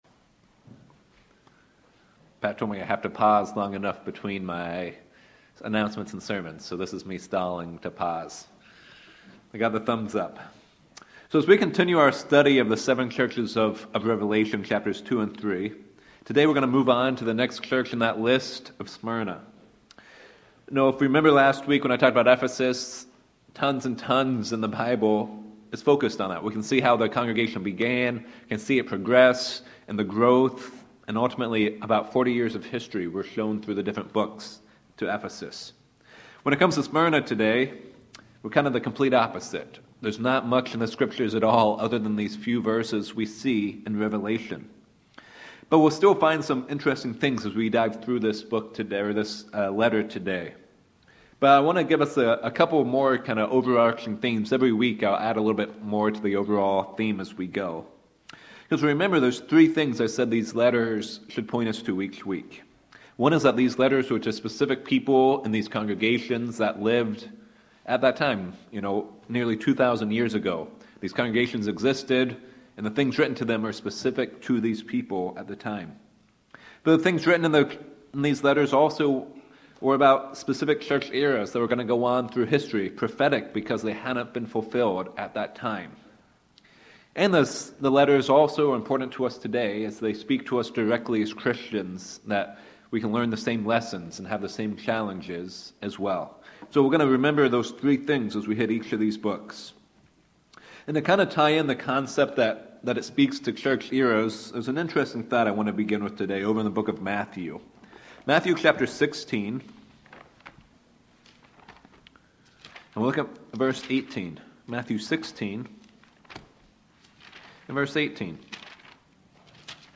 Sermons
Given in Sacramento, CA Reno, NV